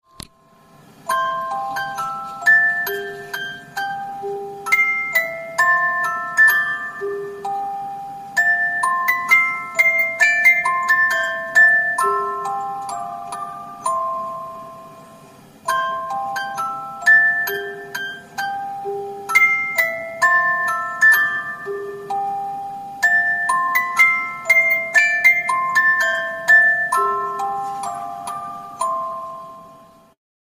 （八高新聞第2号 昭23．7．1） 関連リンク 校歌(オルゴールによる旋律) ［その他：359KB］